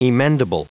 Prononciation du mot emendable en anglais (fichier audio)
Prononciation du mot : emendable
emendable.wav